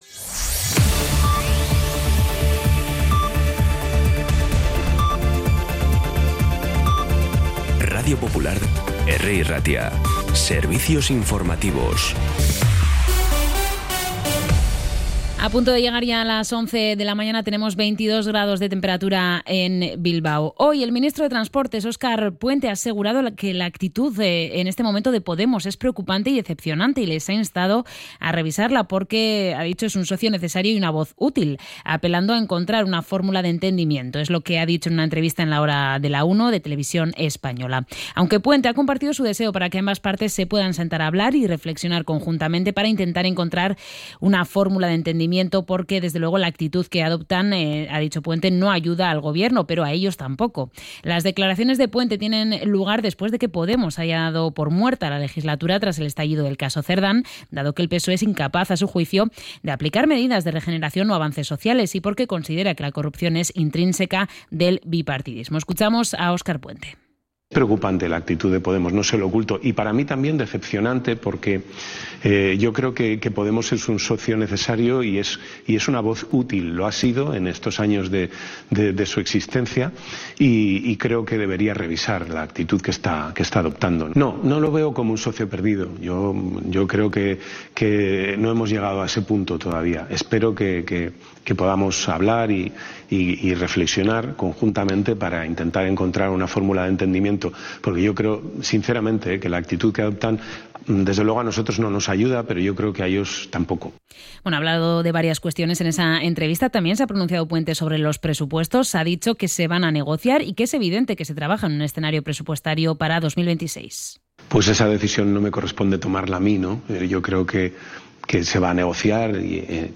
Información y actualidad desde las 11 h de la mañana